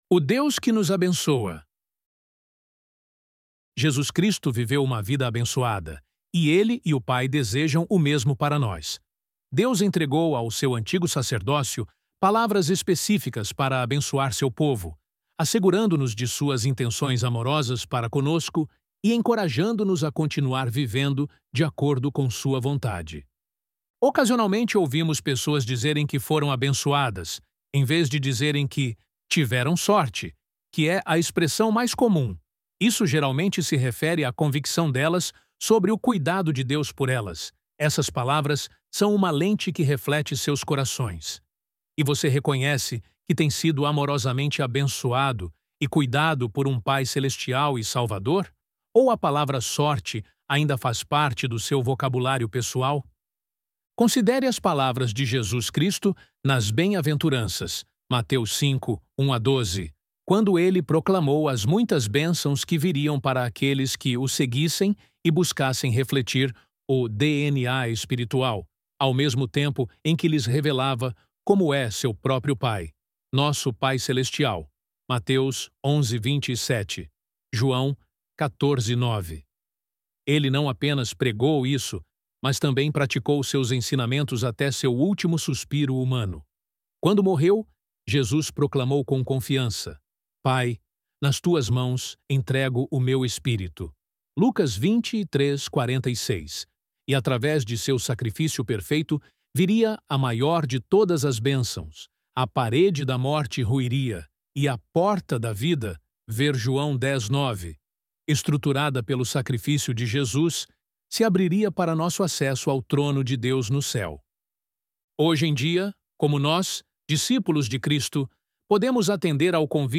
ElevenLabs_O_Deus_Que_Nos_Abençoa.mp3